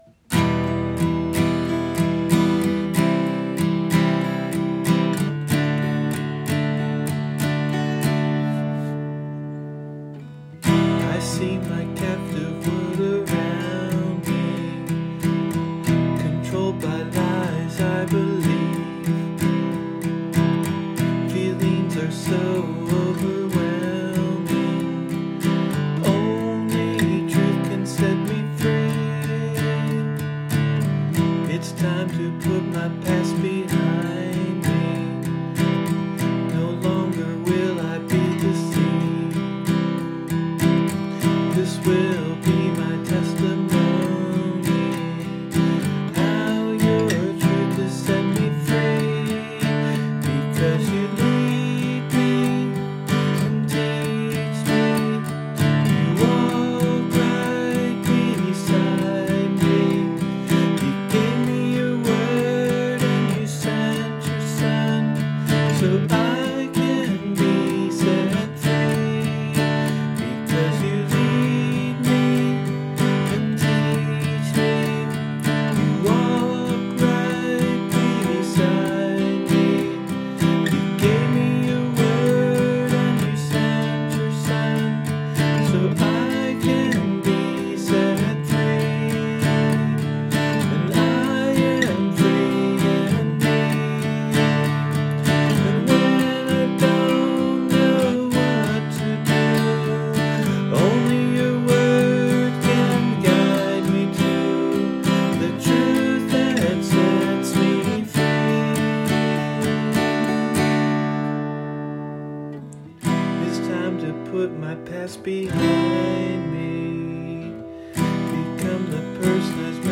Here’s the client demo giving me an idea of his song:
testimony-client-demo.mp3